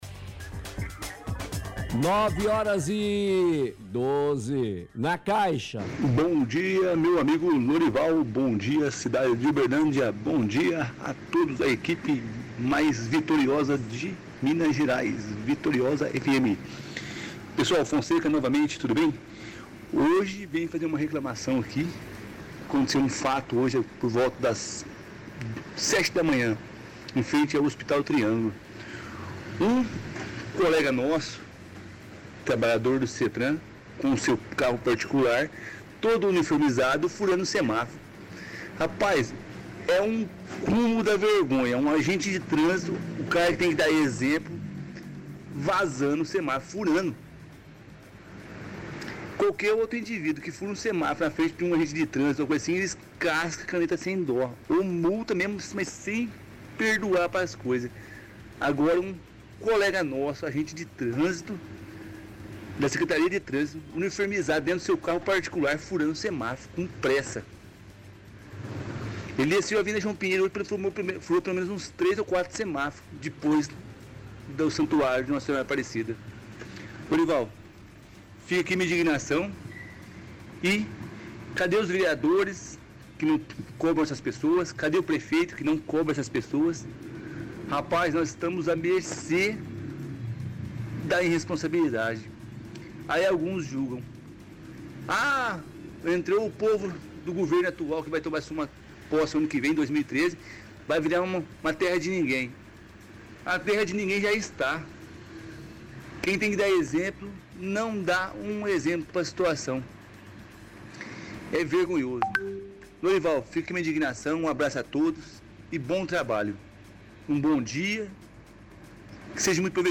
– Ouvinte relata caso de agente de trânsito, uniformizado e dentro do próprio carro particular, passando por semáforo fechado. Questiona onde está o prefeito que não cobra essas pessoas.